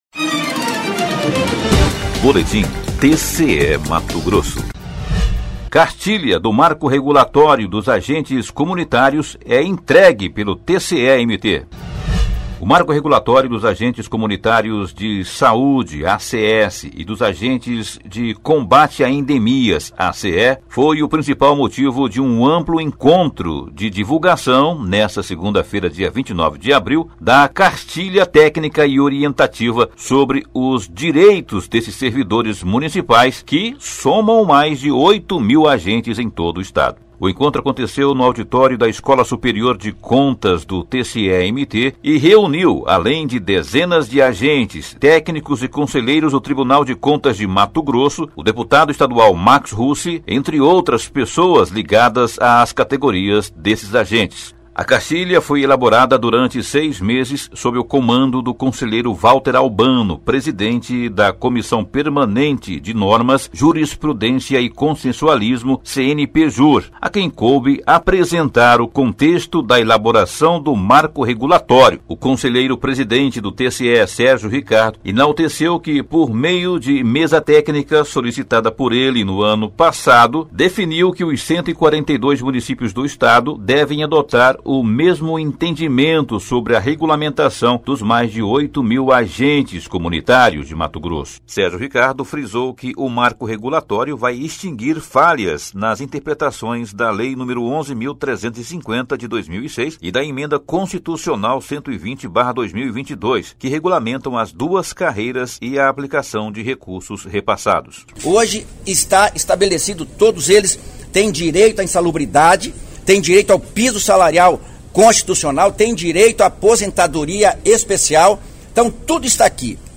Sonora: Sérgio Ricardo – conselheiro-presidente do TCE-MT
Sonora: Guilherme Antonio Maluf – conselheiro presidente da COPSPAS do TCE-MT
Sonora: Max Russi – deputado estadual